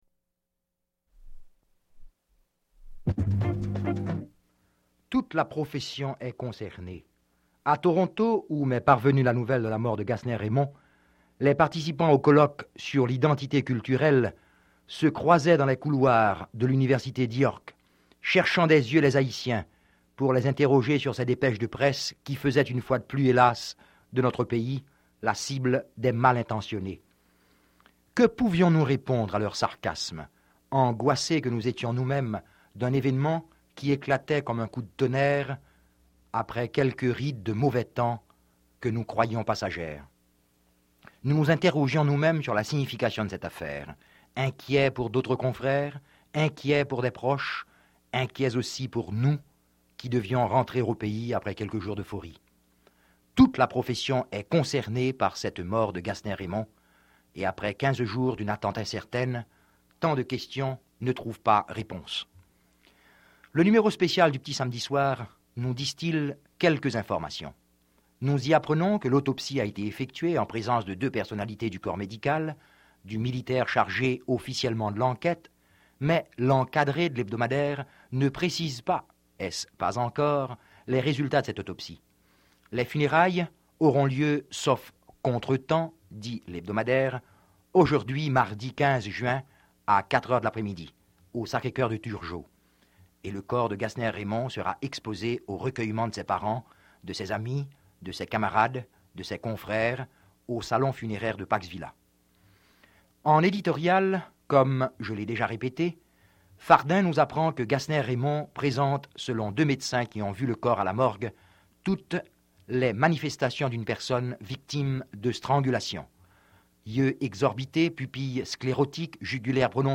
Editorial
Speaker: Dominique, Jean L. (Jean Leopold)